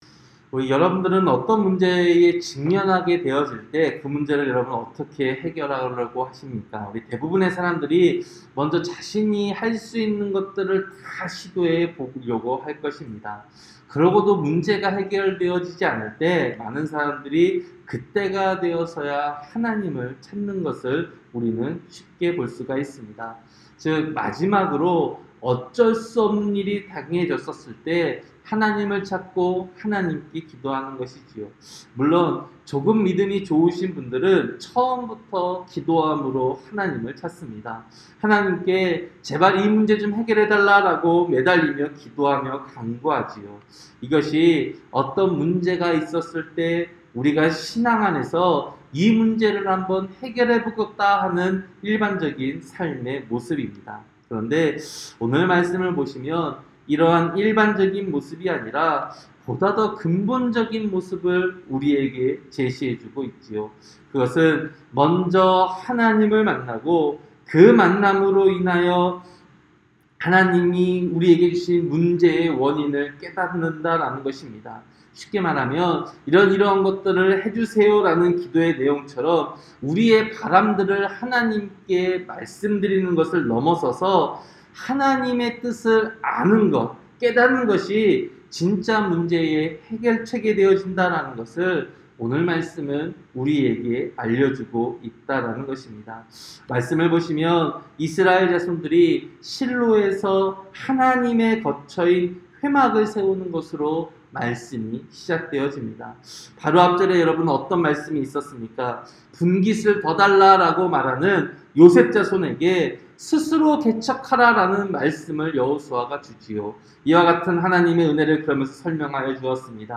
새벽설교-여호수아 18장